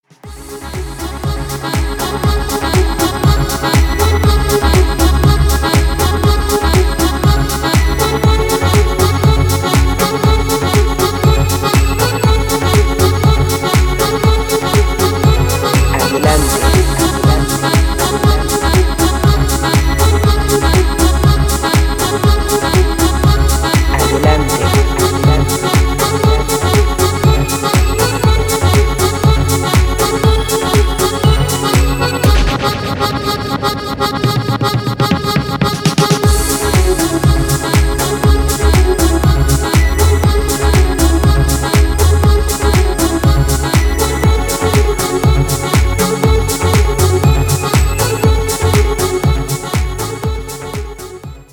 • Качество: 320, Stereo
зажигательные
Electronic
EDM
без слов
танцевальная музыка
Стиль: deep house